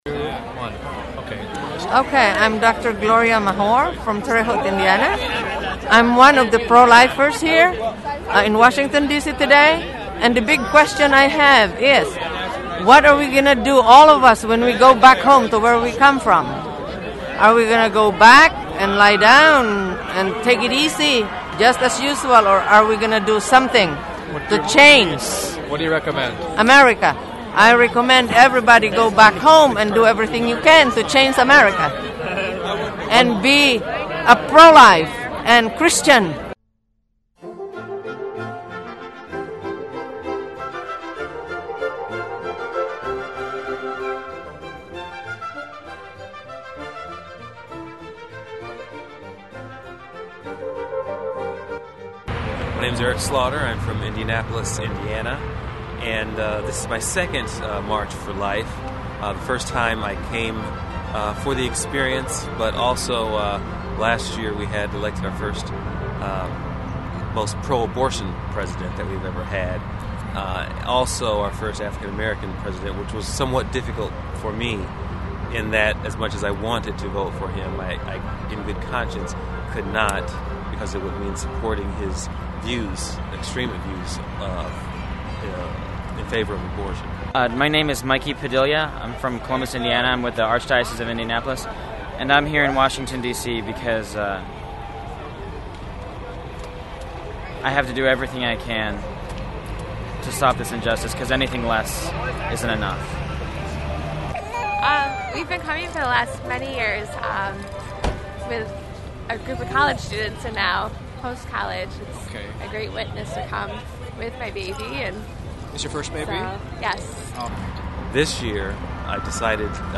Video – Roving Reporter #106: Interviews From DC March for Life
The friars from Bloomington, IN filmed this while in DC last January while on the March for Life.